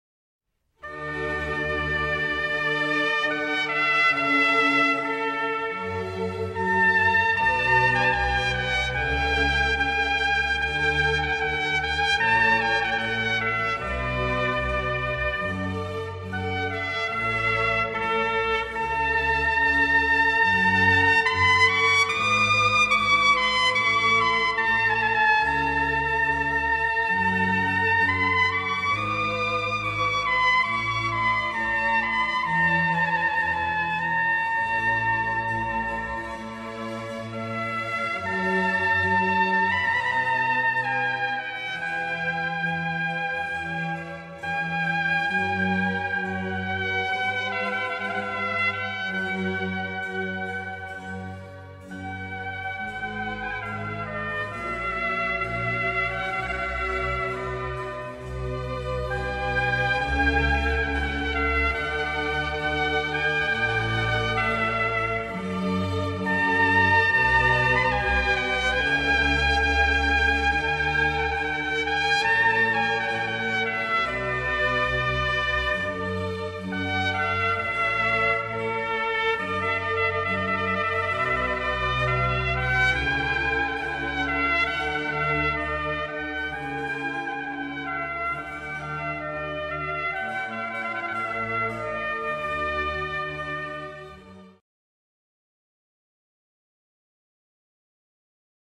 Ce qui caractérise la famille des cuivres, ce n'est pas le matériau, mais le fait qu'ils partagent le même type d'embouchure.
trompette, Telemann